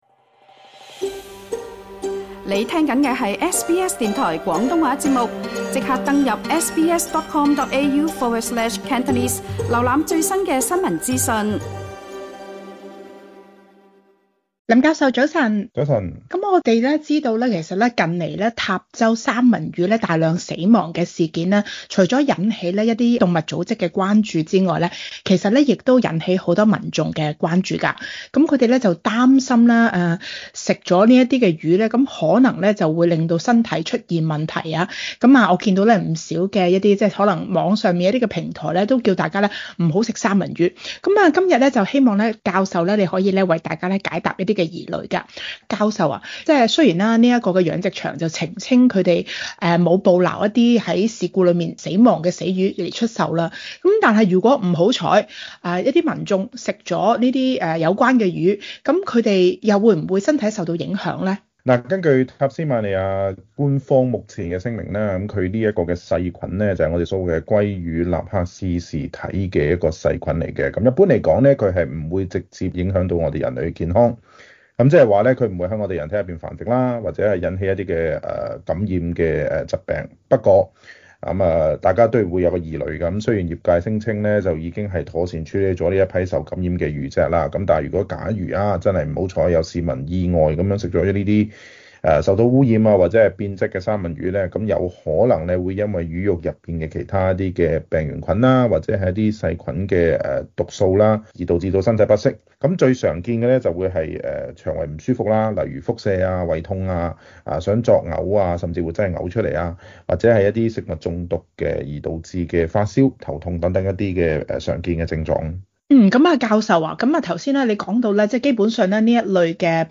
Source: Pixabay SBS廣東話節目 View Podcast Series Follow and Subscribe Apple Podcasts YouTube Spotify Download (4.98MB) Download the SBS Audio app Available on iOS and Android 食用被污染的三文魚會否影響自身健康？